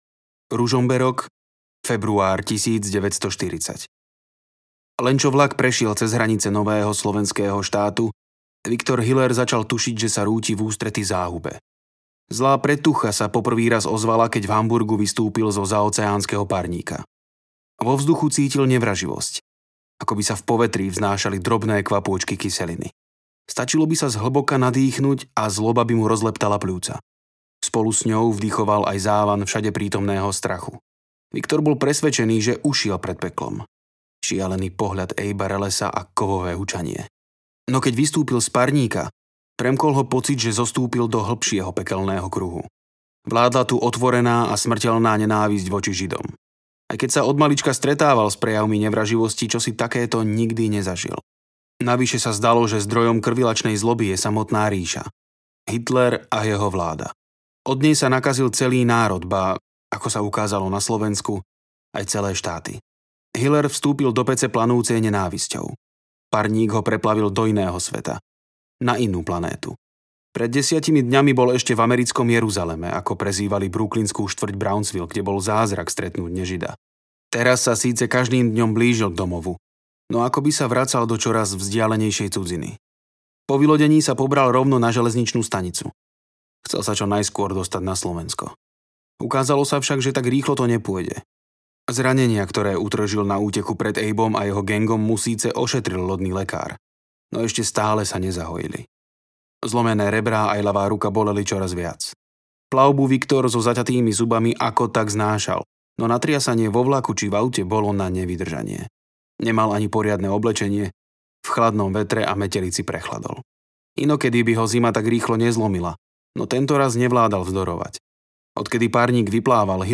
Na smrť 2 audiokniha
Ukázka z knihy